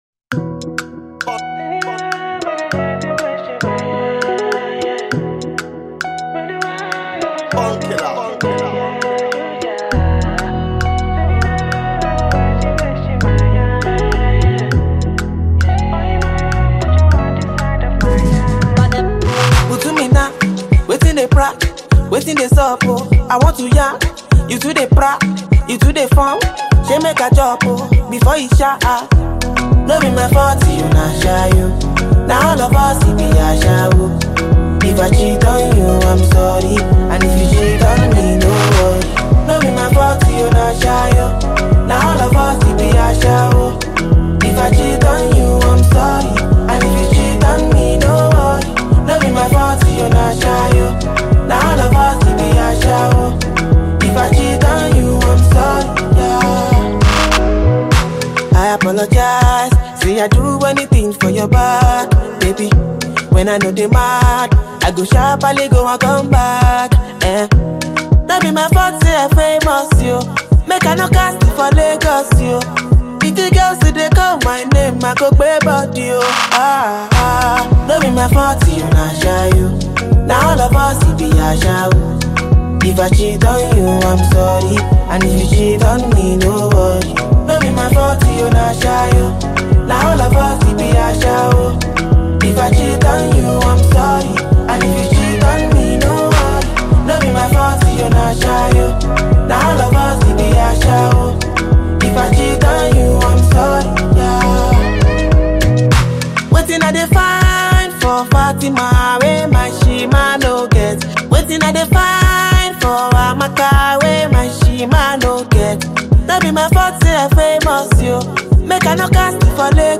With its upbeat tempo and catchy sounds